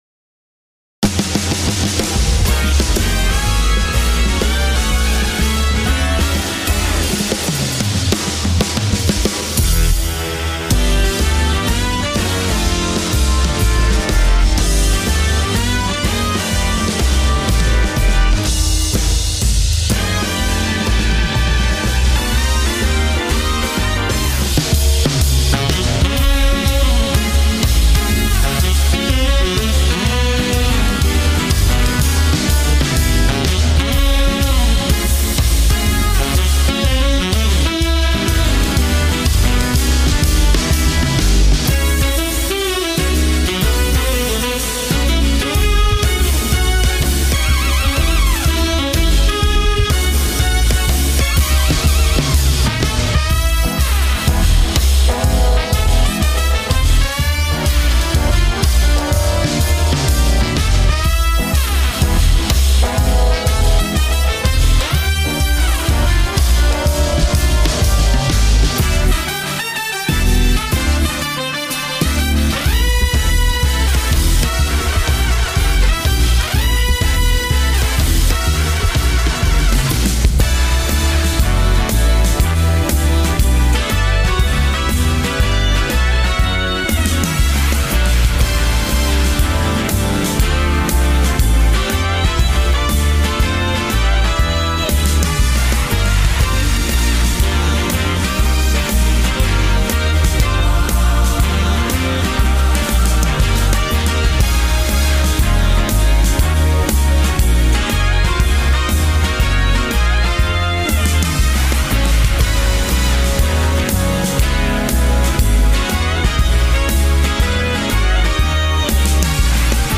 Welcome To Fabulous Las Vegas - Jazz, Band, Pop, Rock - Young Composers Music Forum
Fantastic melody!
Jazz